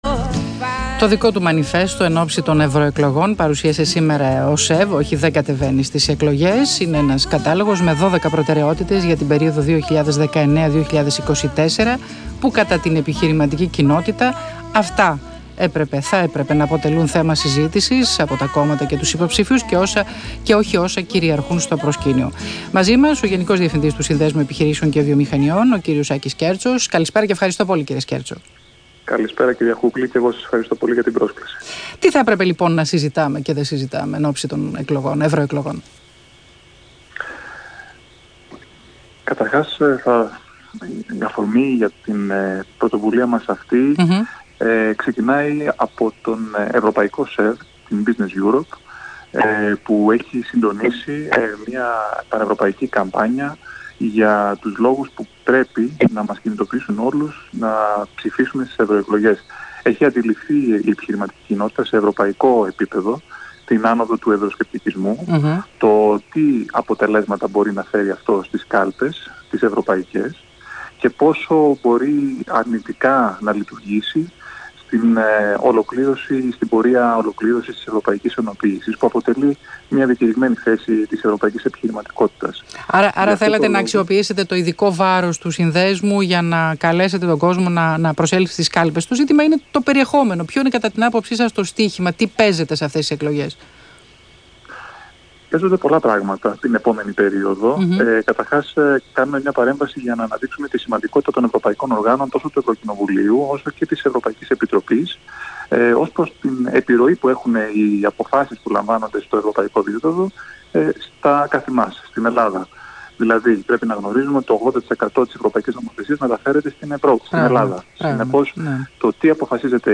Συνέντευξη του Γενικού Διευθυντή του ΣΕΒ, κ. Άκη Σκέρτσου στον Ρ/Σ ΑΘΗΝΑ 984, 15/5/2019